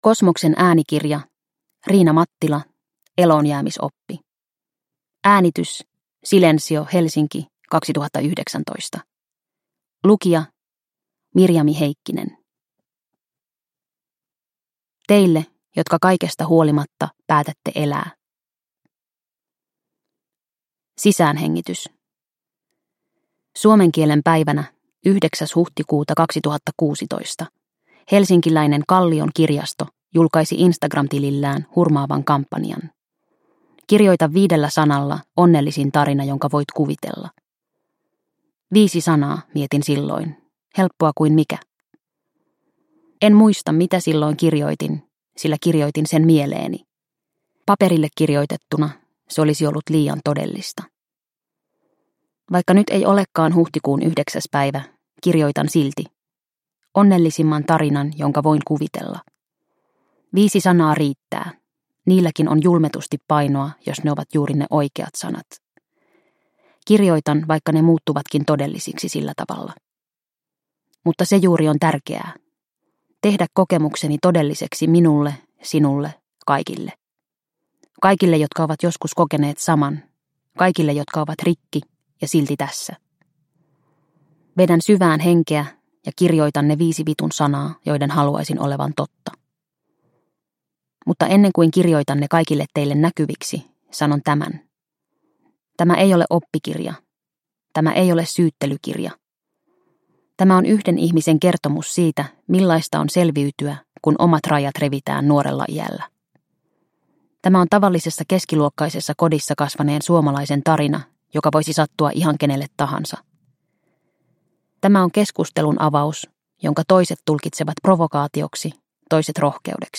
Eloonjäämisoppi – Ljudbok – Laddas ner